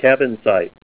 Help on Name Pronunciation: Name Pronunciation: Cavansite + Pronunciation
Say CAVANSITE Help on Synonym: Synonym: ICSD 1906   PDF 25-182